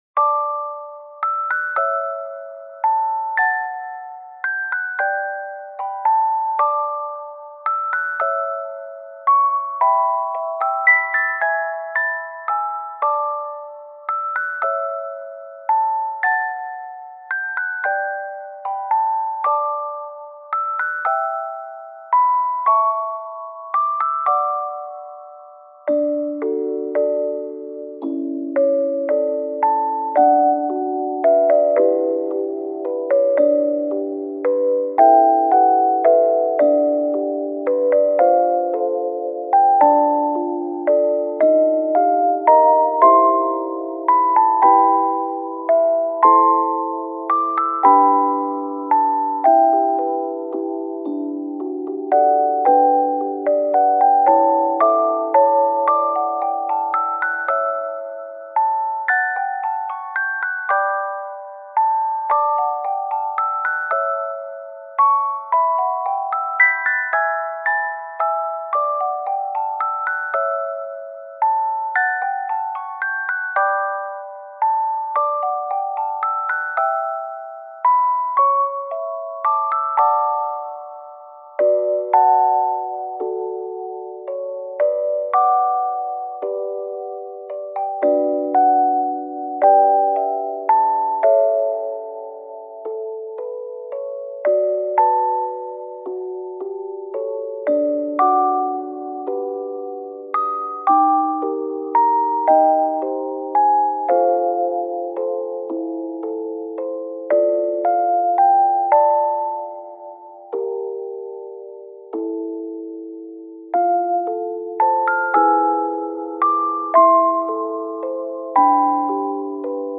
睡眠用の、静かなオルゴールBGMです。